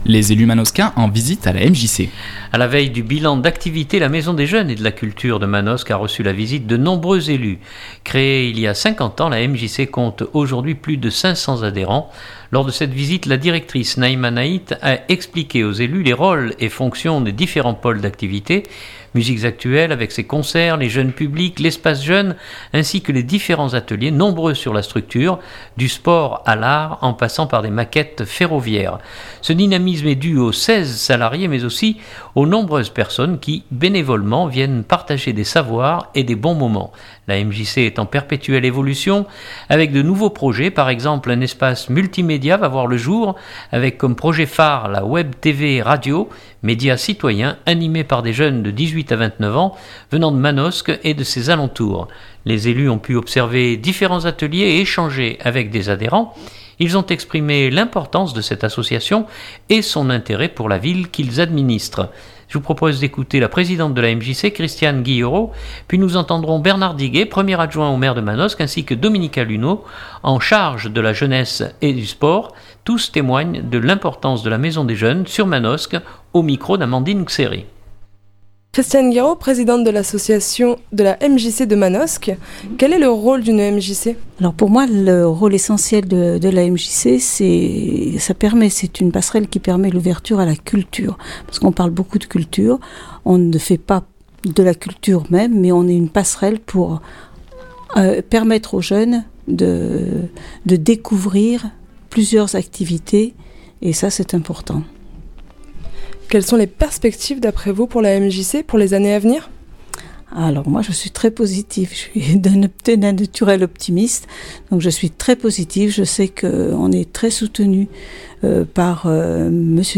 Journal du 2017-03-02 Mjc Manosque.mp3 (10.62 Mo)